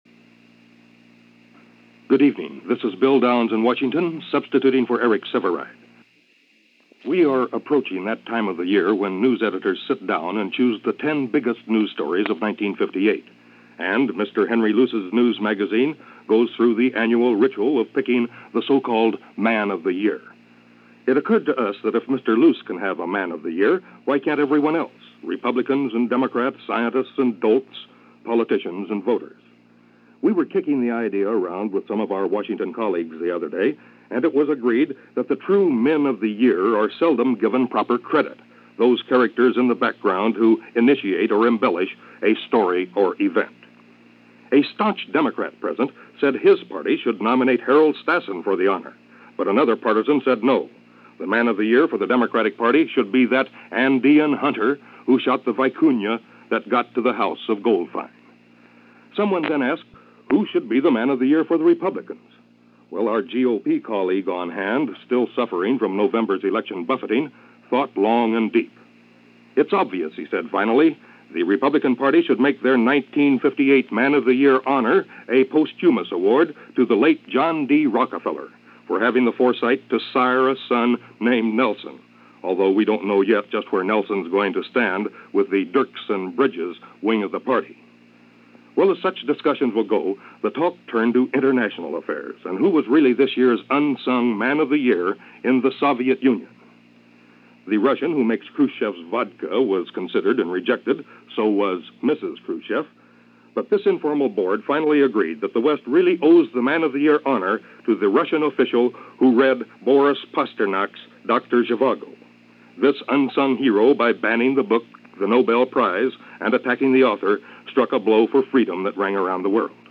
And to give you some idea, here is that broadcast of Bill Downs’ Commentary from CBS Radio in December of 1958.